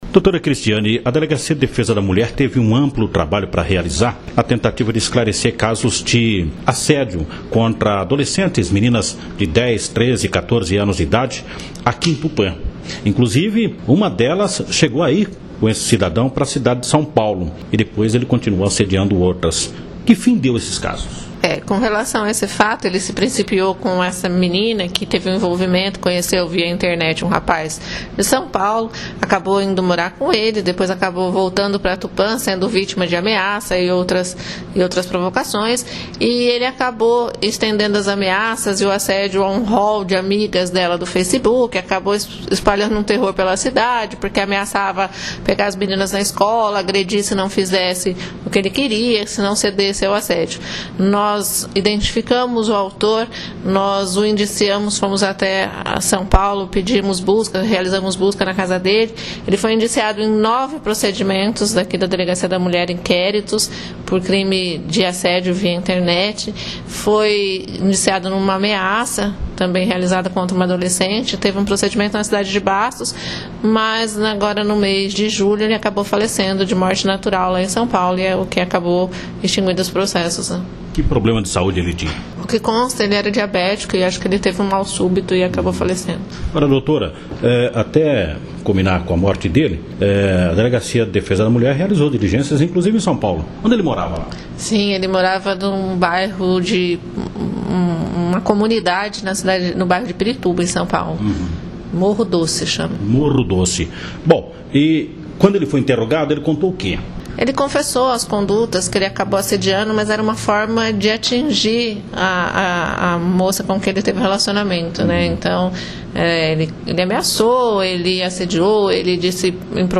MATÉRIA ALICIADOR FACEBOOK